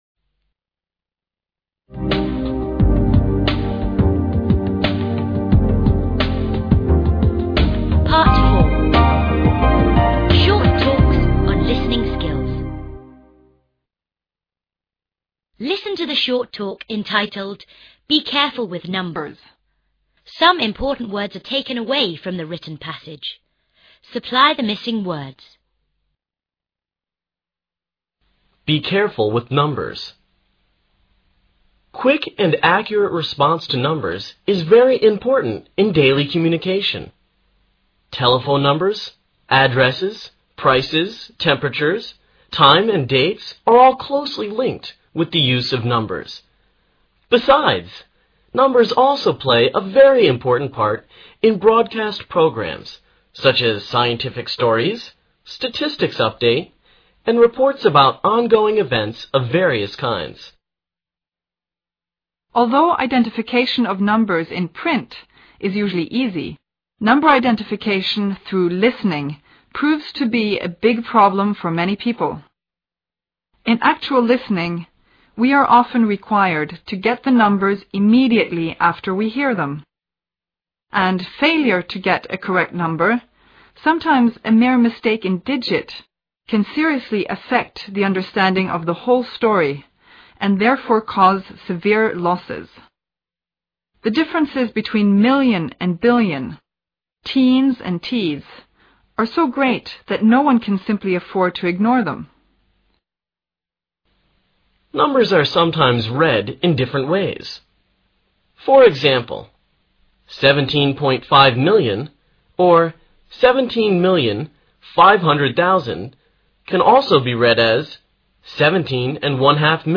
Part 4. Short talks on listening skills.